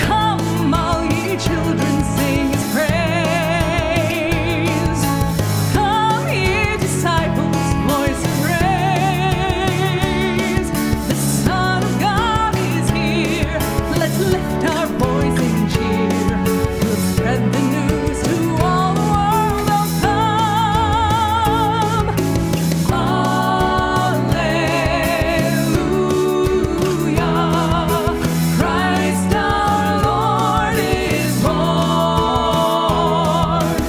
choral song arrangements